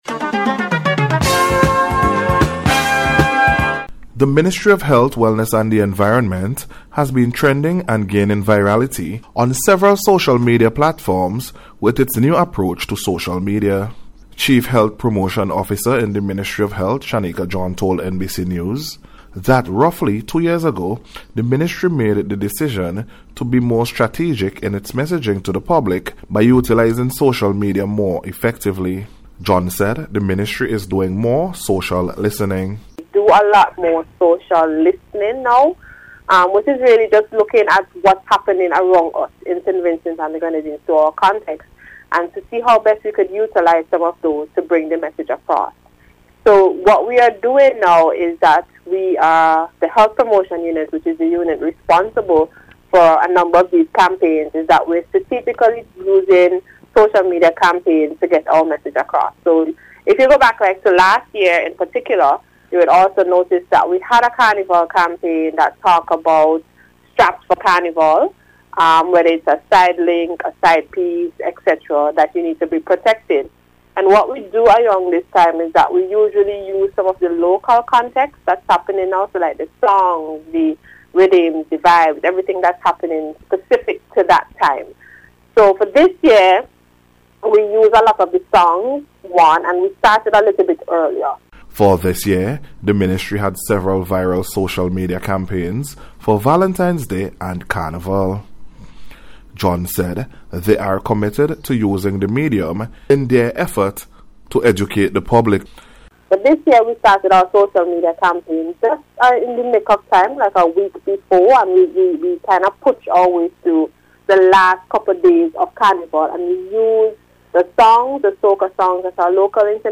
SVG-HEALTH-MEDIA-REPORT.mp3